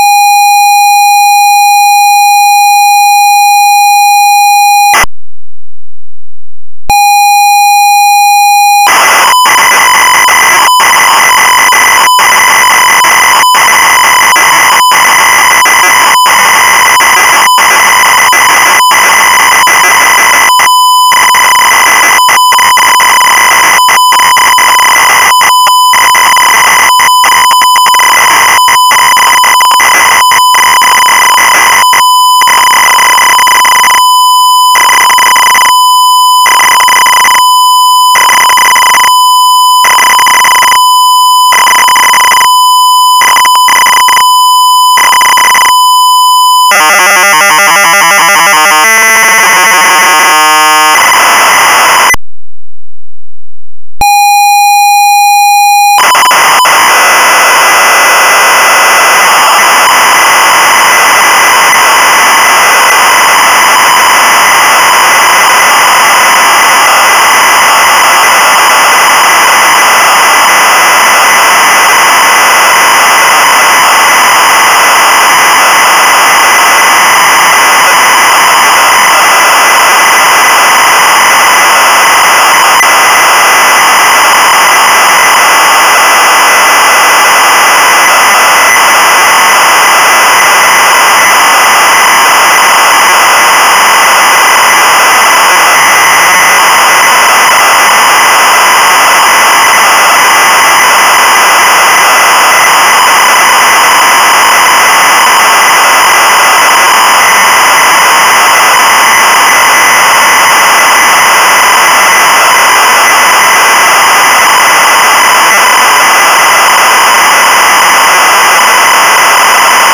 Puteți utiliza unul din fișierele de tip ROM atașate (cu extensia WAV) pentru a încărca sistemul de operare pe un computer CIP-01.
(6.53 MB) - ROM pentru CIP-01 inclus pe caseta demonstrativă